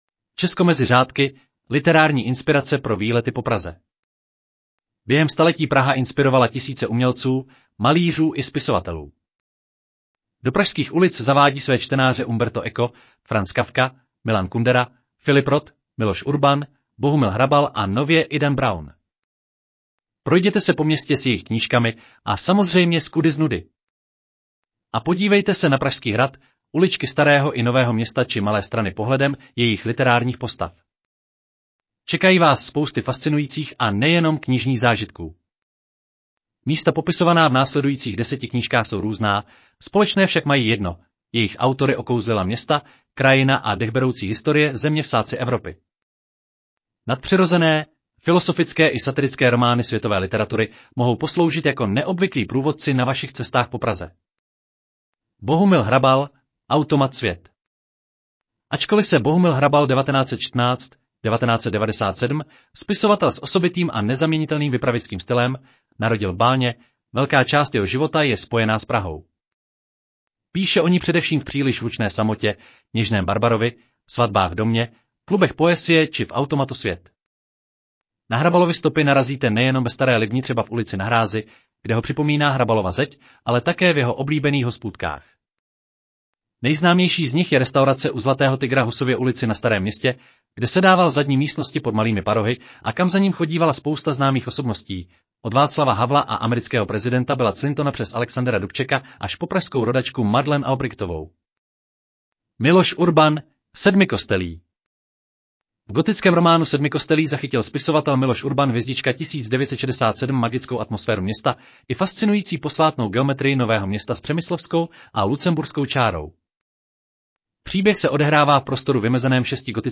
Audio verze článku Česko mezi řádky: 10 literárních inspirací pro výlety po Česku